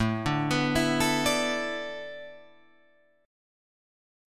Asus2sus4 chord